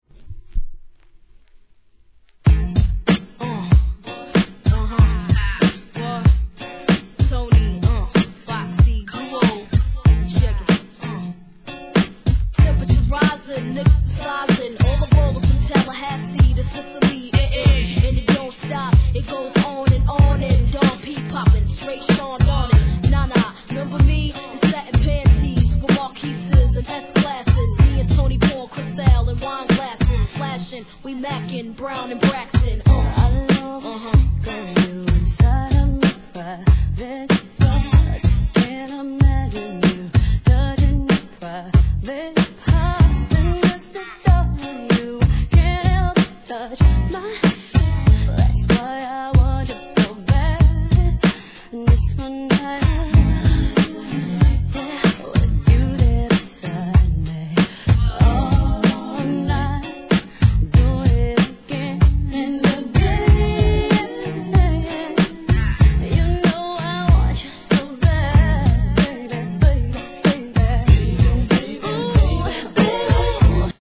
HIP HOP/R&B
GROOVE MIX DANCE HALL MIX ALBUM ver.